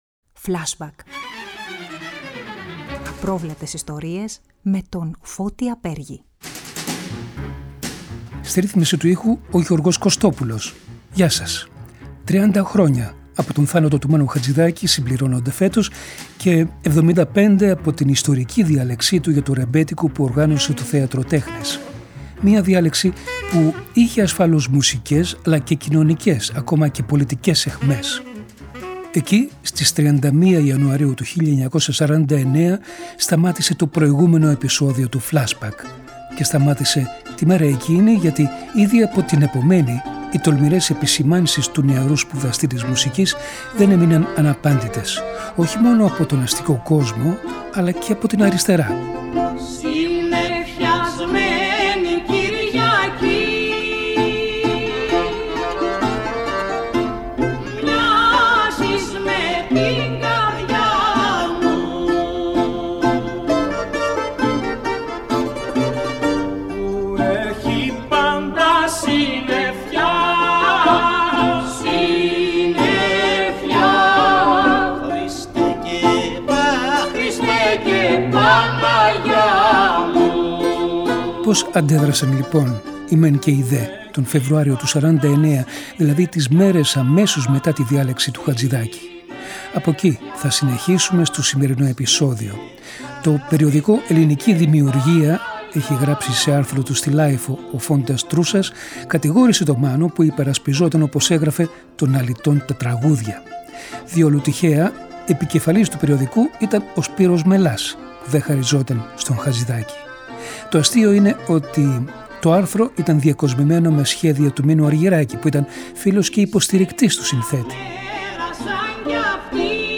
Ο Χατζιδάκις και ο Γιώργος Ζαμπέτας παίζουν μαζί Βαμβακάρη στο Τρίτο.